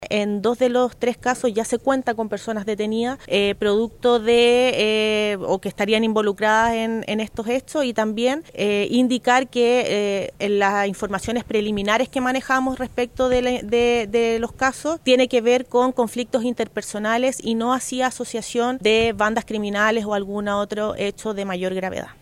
Sobre los tres homicidios, la seremi de Seguridad, Alejandra Romero, notificó que este lunes se detuvo a dos responsables de los hechos en paralelo.